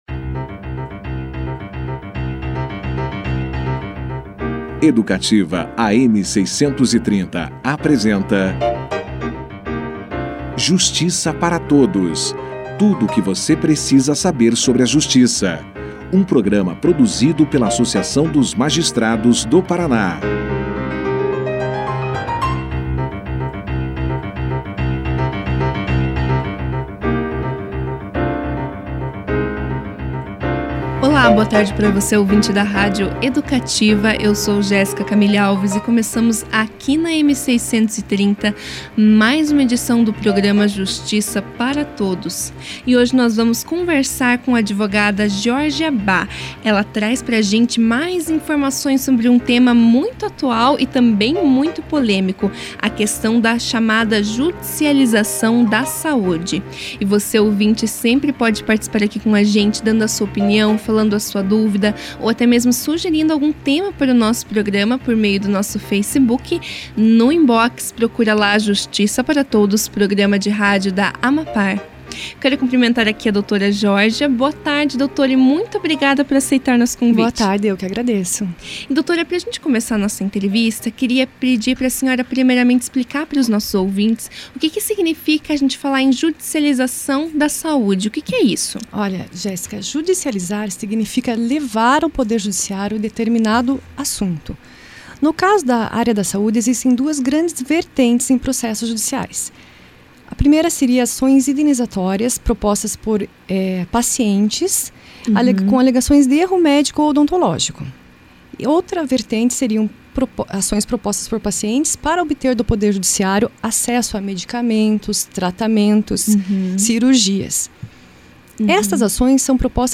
‘‘As principais especialidades questionadas são obstetrícia, ginecologia, traumatologia, ortopedia e cirurgia plástica’’, afirmou e finalizou: ‘‘esse levantamento foi feito pela Sociedade Brasileira de Direito Médico e Bioética, mas o Conselho Regional de Medicina de São Paulo também fez um levantamento há alguns anos atrás que também observou essas especialidades mas incluiu pediatria como uma das especialidades mais acionadas na Justiça’’. Confira a entrevista na íntegra